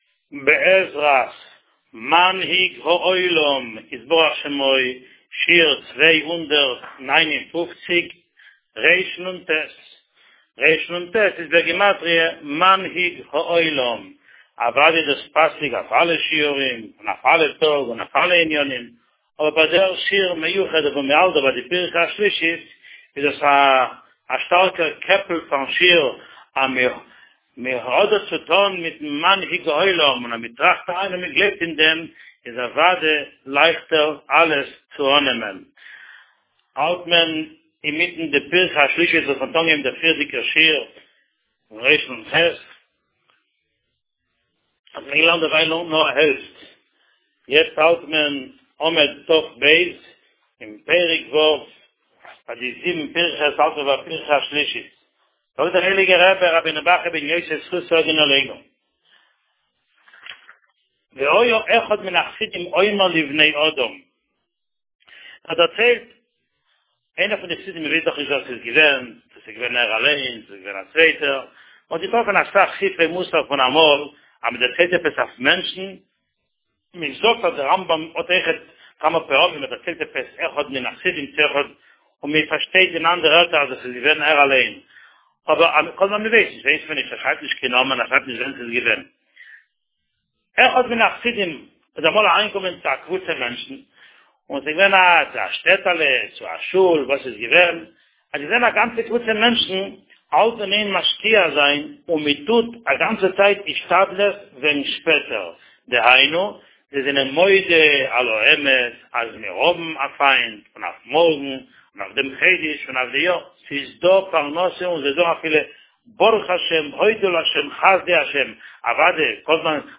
שיעור מספר 259